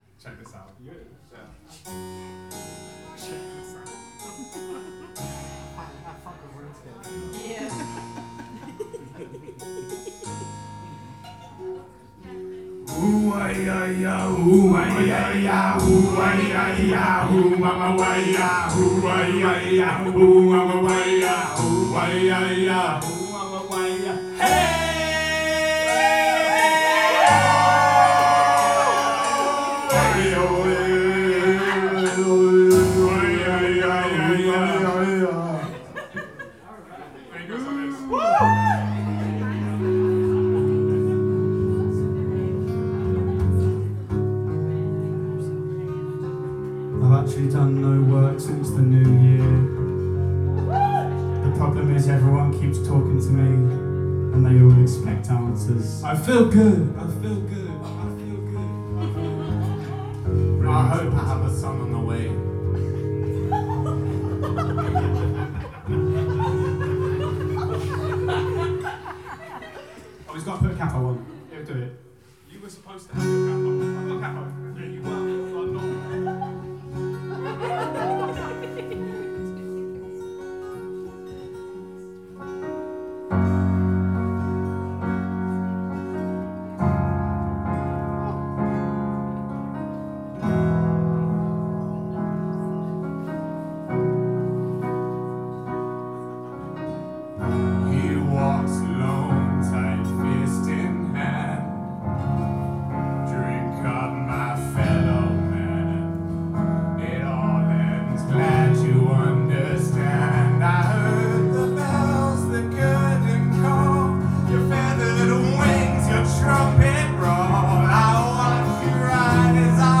Bootleg media